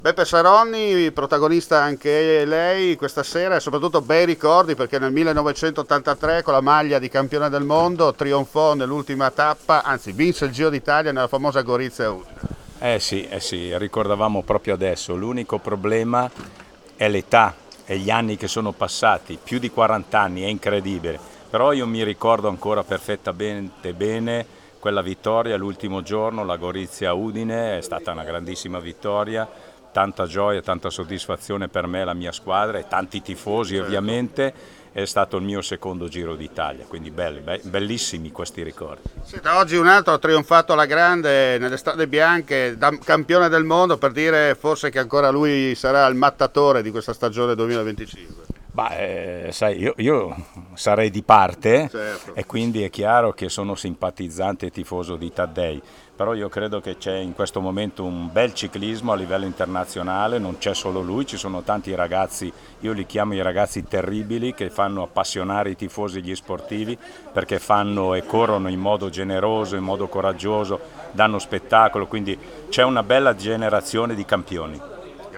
INTERVISTA A GIUSEPPE SARONNI